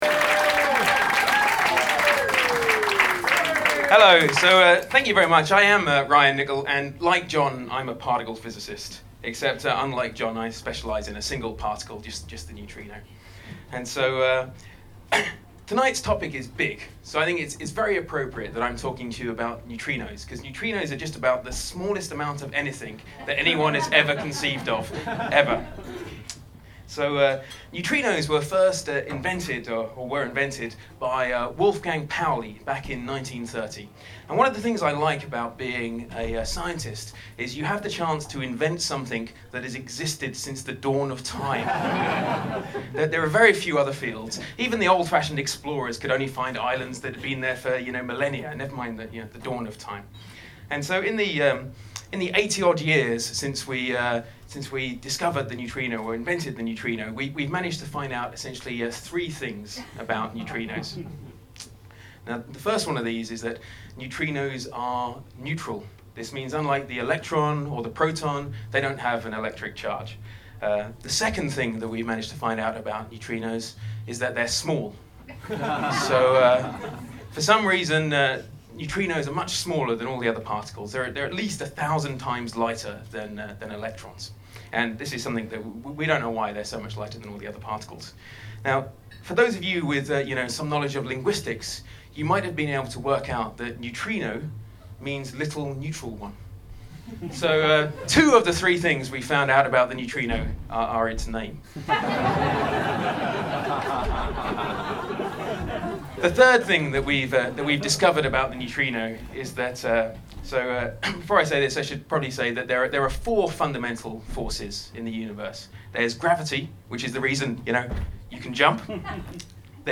took to the Bright Club stage to try their hand at stand-up comedy.